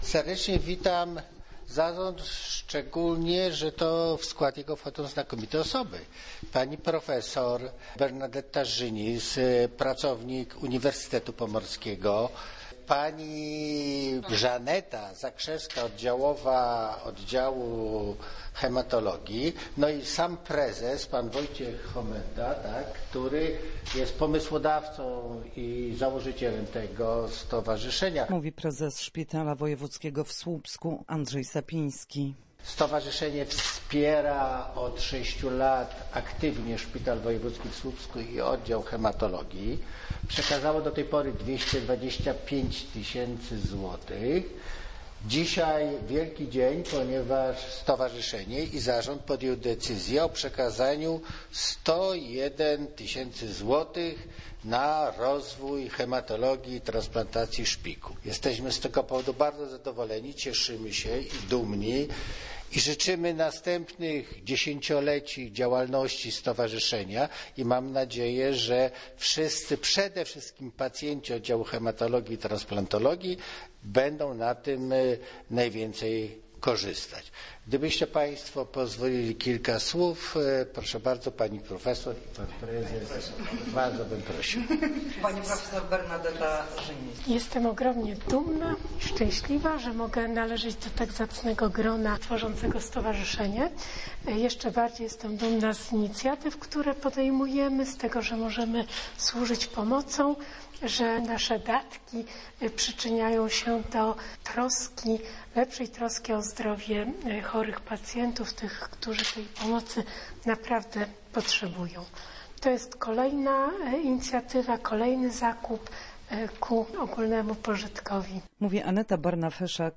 Hematologia_konfa_dl.mp3